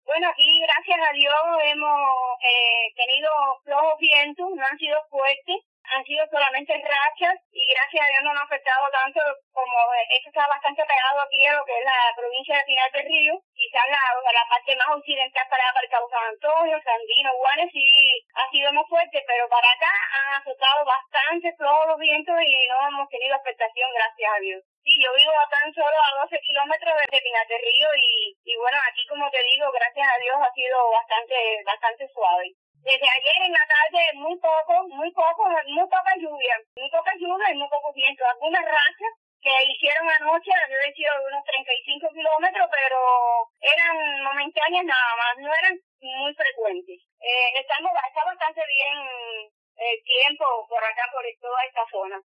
Escúchala aquí: